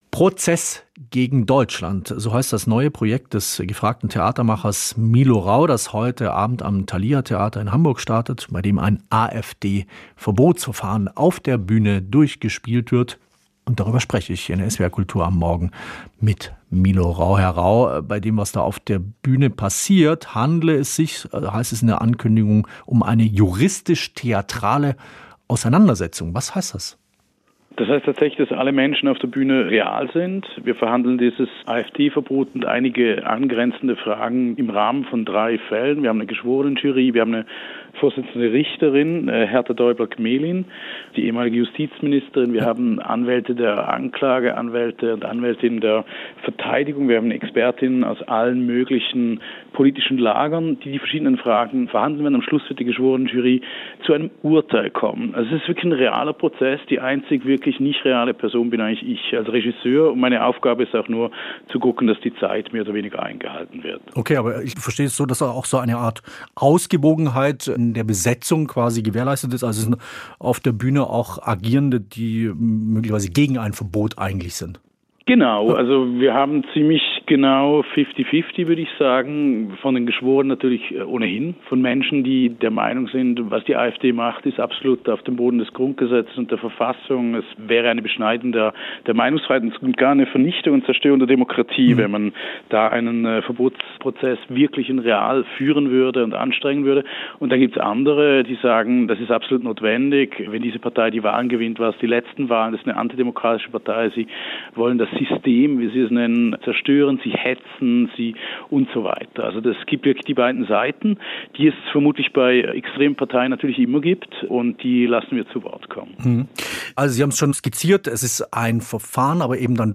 Im Gespräch erklärt er, warum Theater zum Ort politischer Prüfung werden muss.
Interview mit